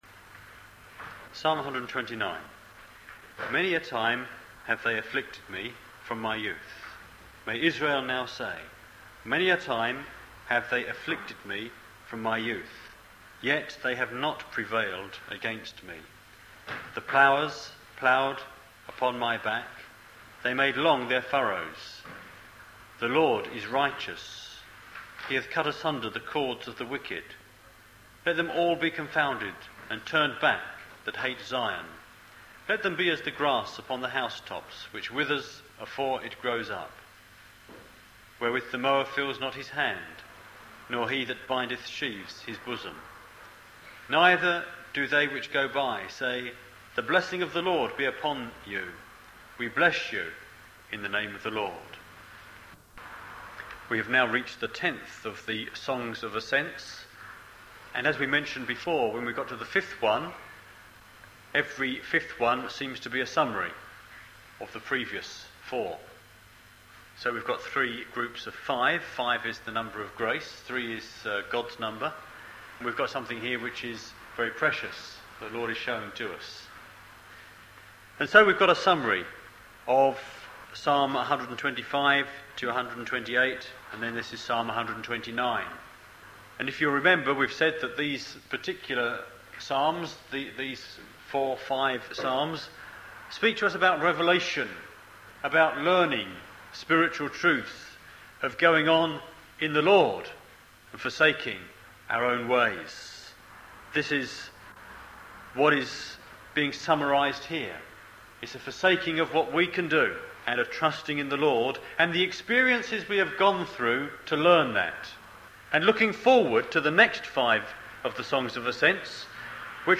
The Church at Gun Hill - Online Bible Study